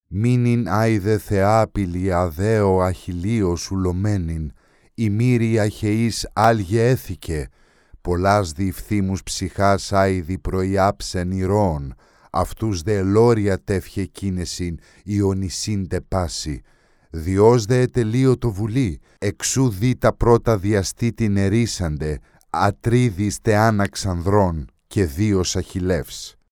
男古希腊14
男古希腊14 古希腊语男声_02 低沉|激情激昂|大气浑厚磁性|沉稳|娓娓道来|科技感|积极向上|神秘性感|调性走心|亲切甜美|感人煽情|素人|脱口秀